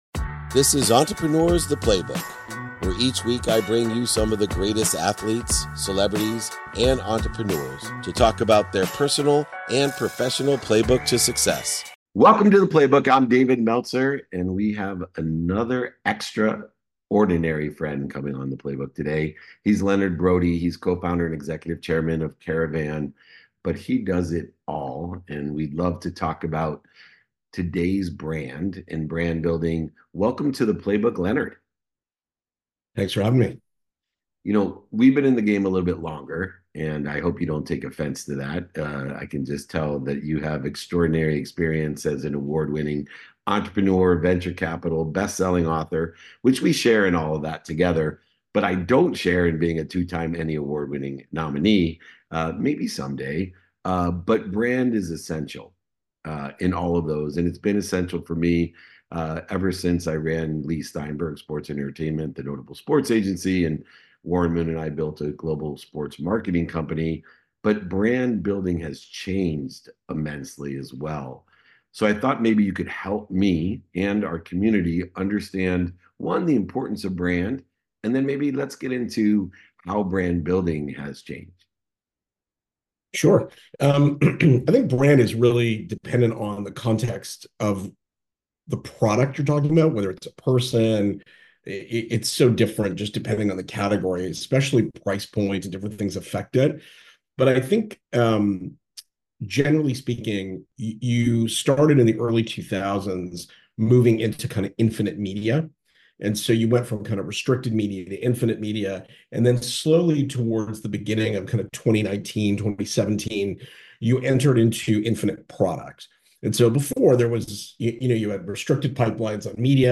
This conversation provides valuable insights into modern brand building and community engagement.